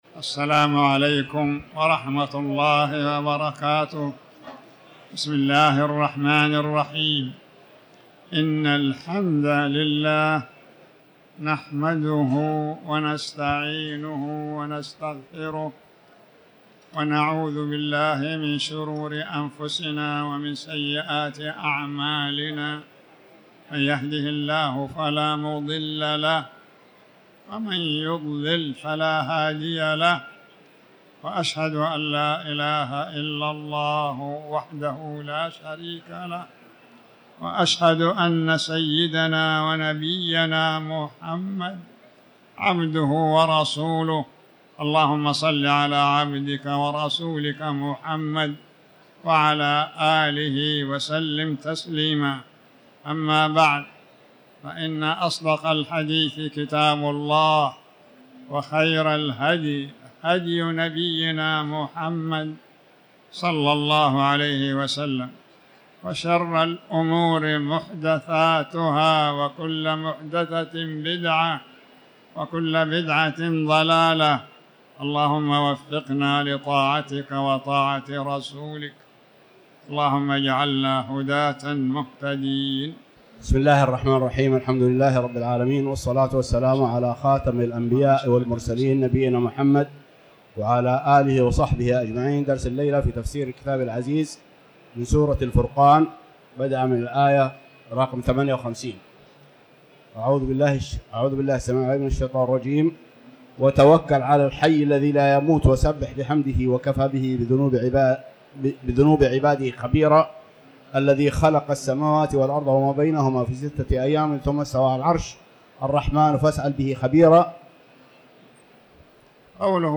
تاريخ النشر ٢٤ شعبان ١٤٤٠ هـ المكان: المسجد الحرام الشيخ